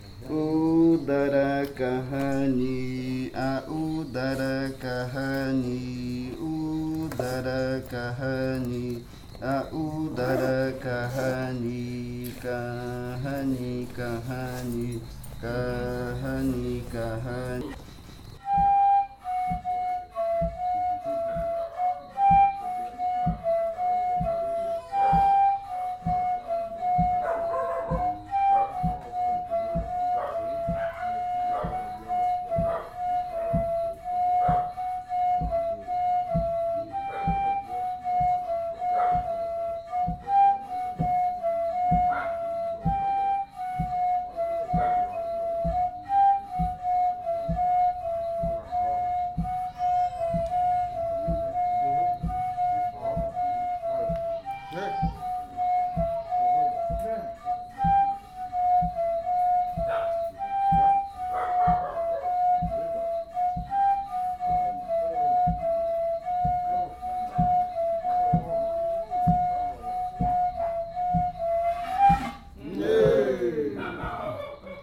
Reribakui_ocaina_2.mp3 (1.64 MB)
Leticia, Amazonas, (Colombia)
Canto Udara kajañi (lengua ocaina) e interpretación del canto en pares de reribakui.
Udara xaxañi chant (ocaina language) and performance of the chant in reribakui flutes.
This recording is part of a collection resulting from the Kaɨ Komuiya Uai (Leticia) dance group's own research on pan flutes and fakariya chants.